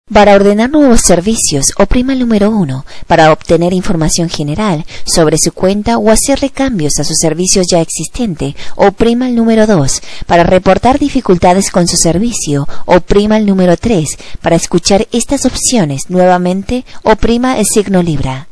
Mensaje Automático / Automated Message